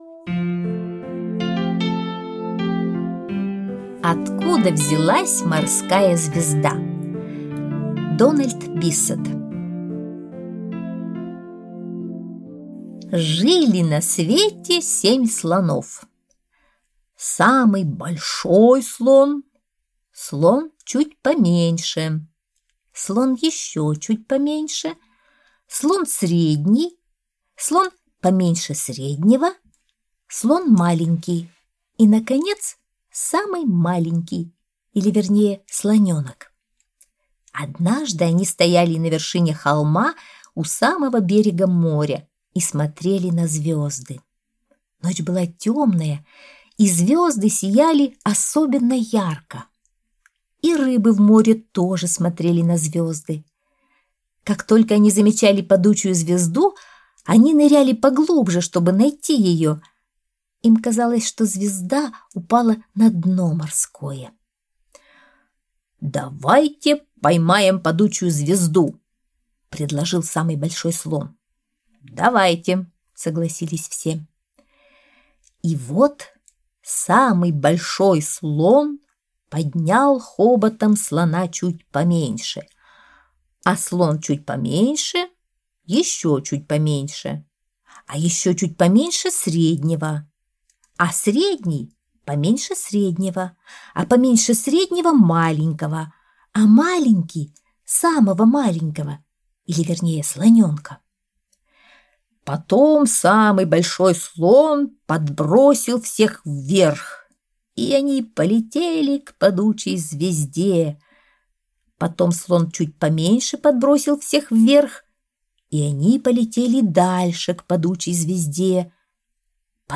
Аудиосказка «Откуда взялась морская звезда»